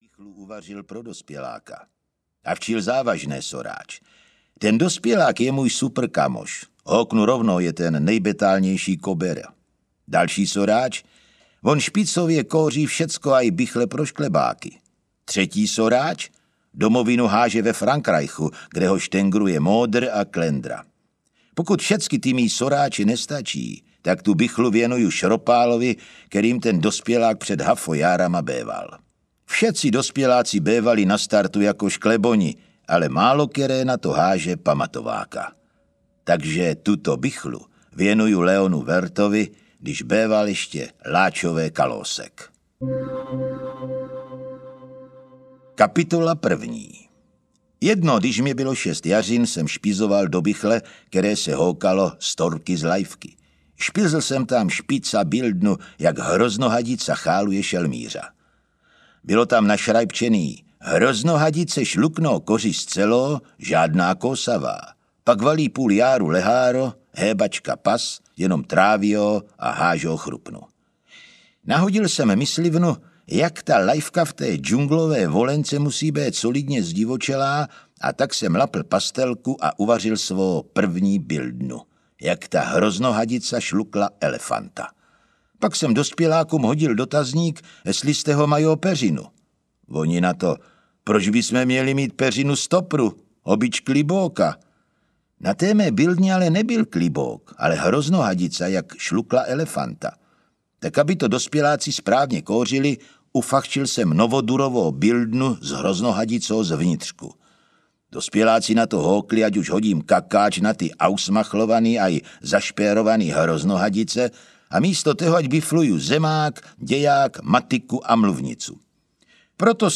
Malé principál audiokniha
Malý princ v brněnském hantecu je konečně na světě!
Ukázka z knihy
• InterpretZdeněk Junák